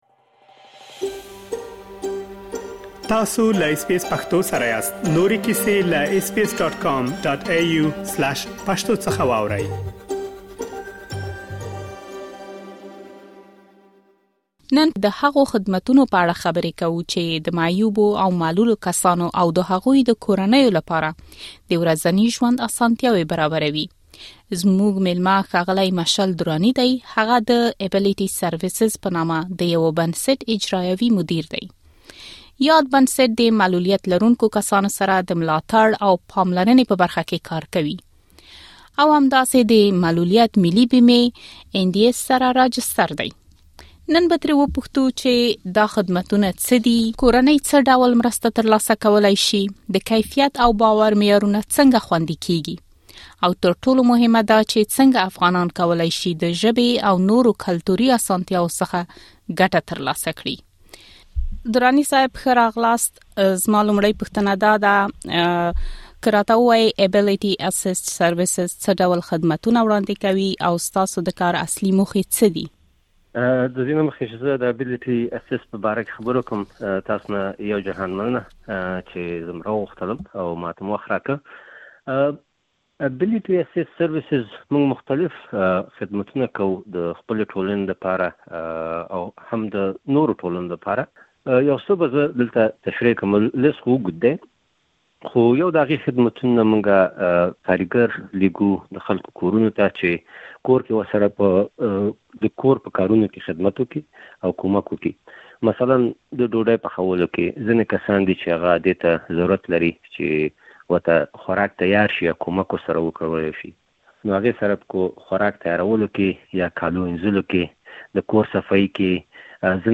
او تر ټولو مهمه دا چې څنګه افغانان کولی شي د ژبې او نورو کلتوري اسانتیاوو څخه ګټه ترلاسه کړي. مهرباني وکړئ بشپړه مرکه دلته واورئ.